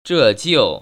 [zhè jiù] 저지우